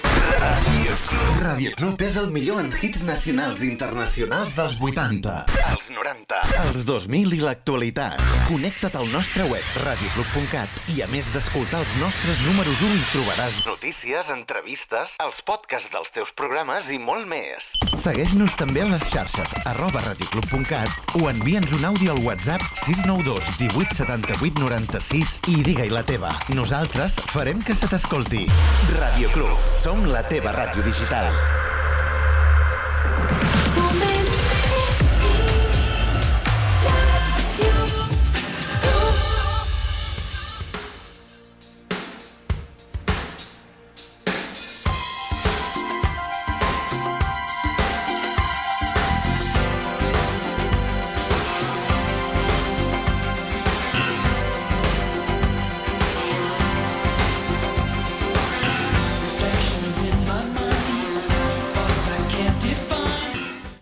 Indicatiu de la ràdio, forma de contactar-hi i tema musical